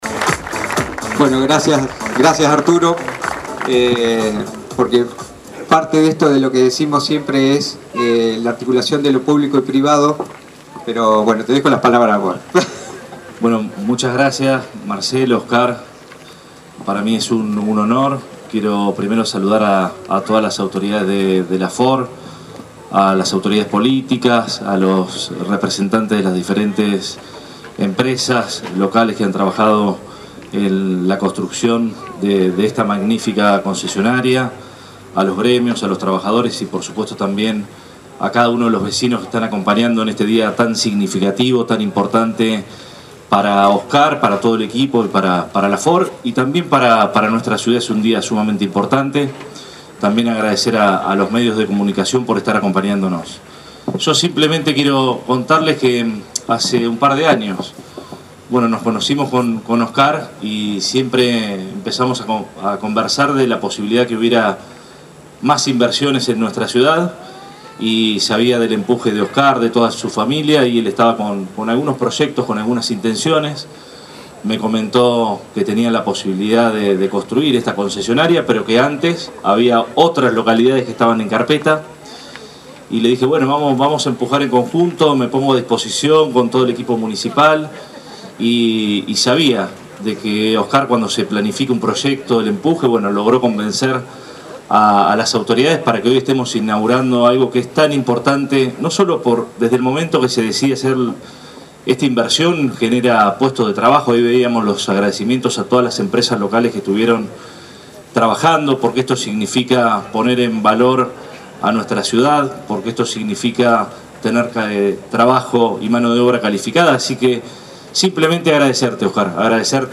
Fuerte inversión para Necochea: el Intendente acompañó la apertura de la nueva concesionaria de Ford – Municipalidad de Necochea